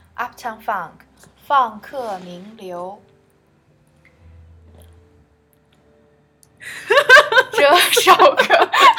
And of course, there was at least one blooper outtake…
uptown-funk-blooper.m4a